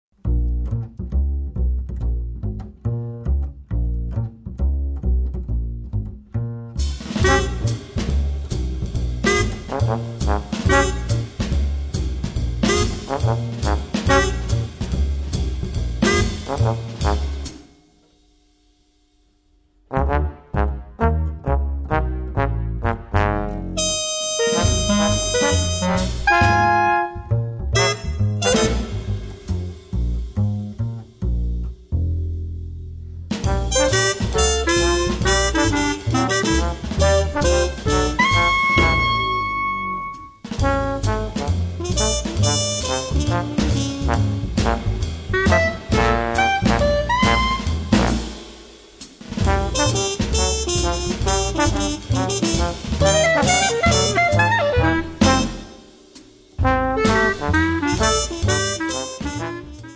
drum
double bass
alto sax and clarinet
trumphet, flugelhorn
trombone